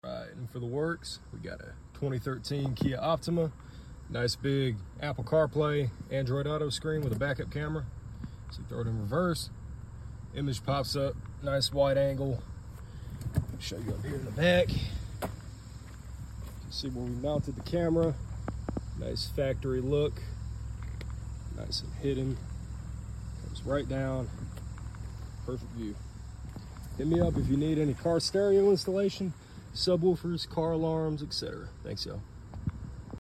Atlanta Car Stereo, subwoofer, remote